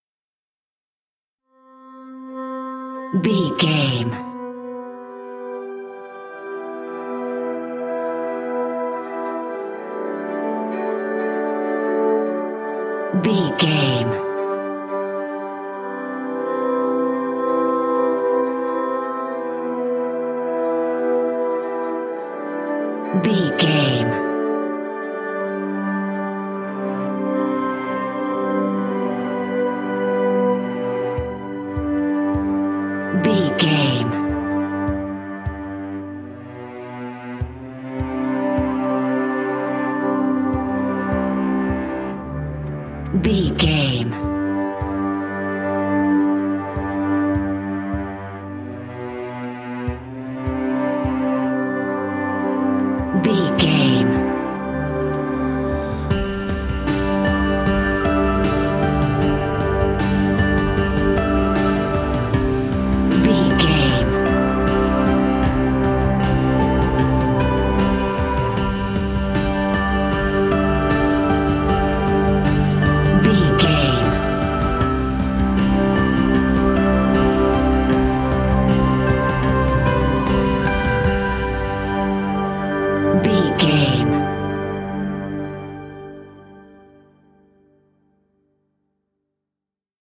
In-crescendo
Ionian/Major
Slow
contemplative
dreamy
melancholy
mellow
serene
cello
electric guitar
synthesiser
percussion
strings